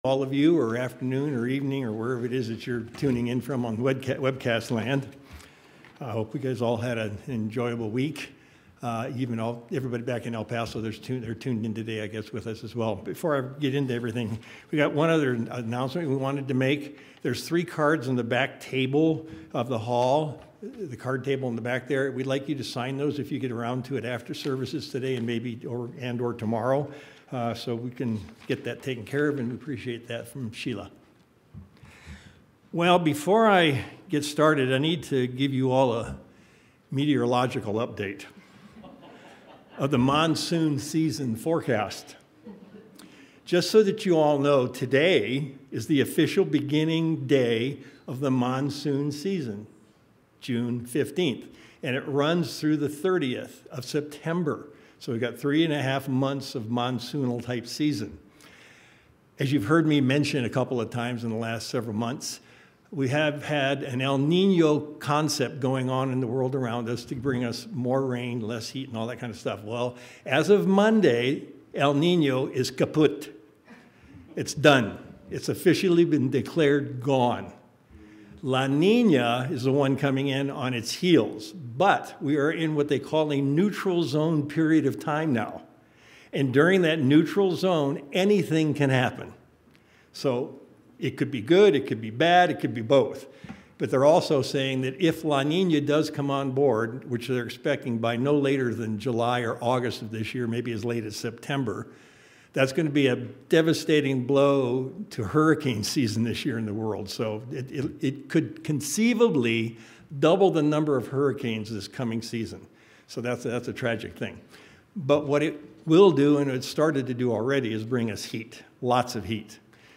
The Power of God's Holy Spirit is something we discuss each year as we near Pentecost. We'll see in today's sermon that the Holy will help control our minds if we follow the lead and we can continue to make great strides in our Christian growth.